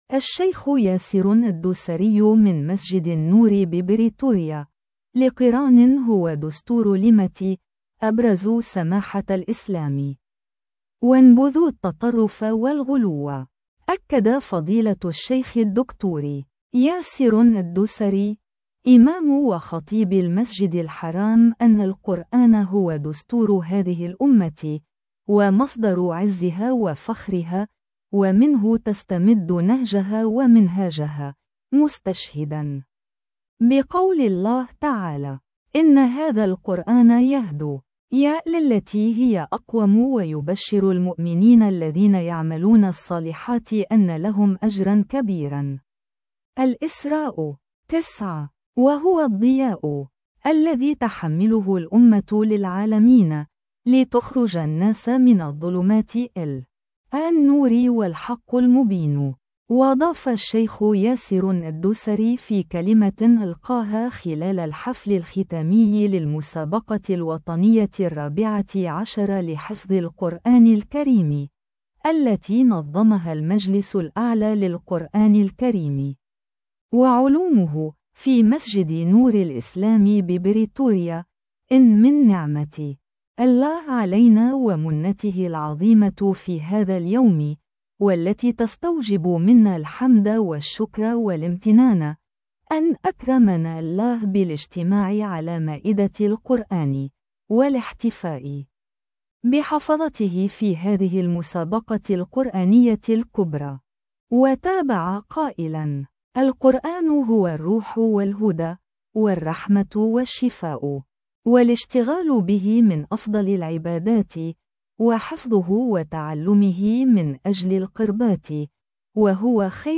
الشيخ ياسر الدوسري من مسجد النور ببريتوريا: القران هو دستور الامة؛ أبرزوا سماحة الإسلام.. وانبذوا التطرف والغلو